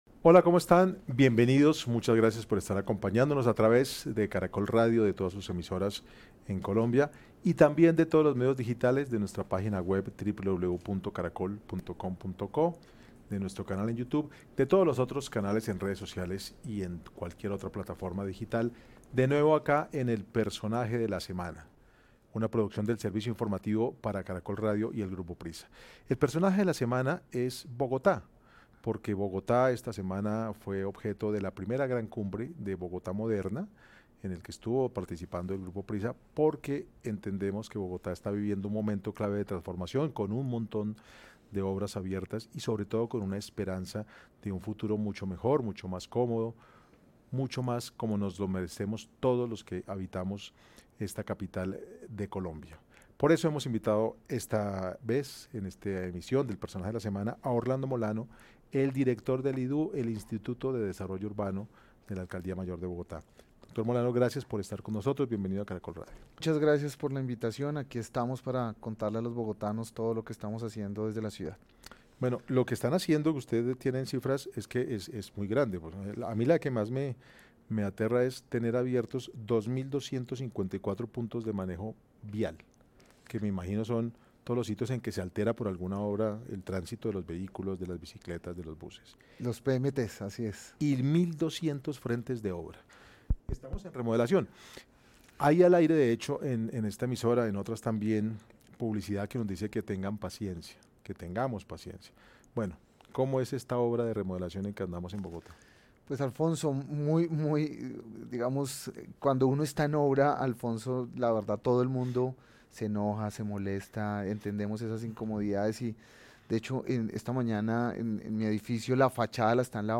El director del Instituto de Desarrollo Urbano (IDU), Orlando Molano, explicó en Caracol Radio que la capital cuenta con más de 1.200 frentes de obra y más de 2.000 puntos de manejo vial activos.